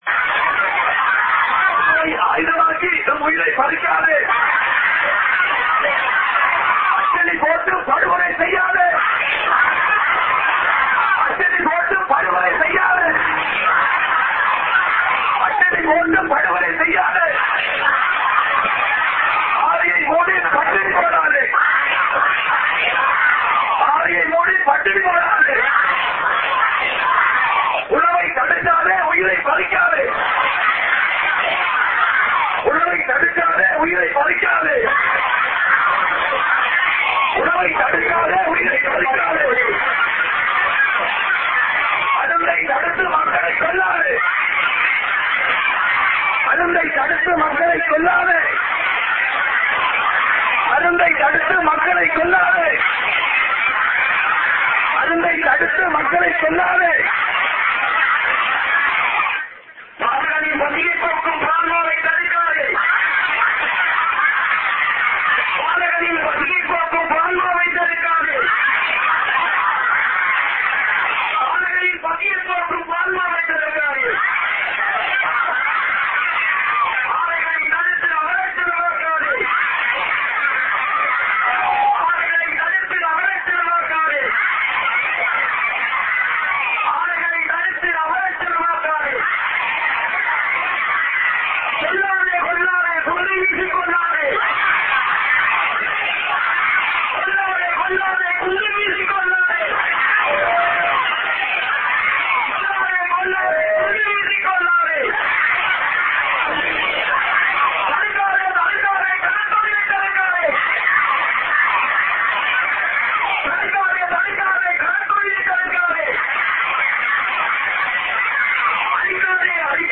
Voice: Slogans by the demonstrators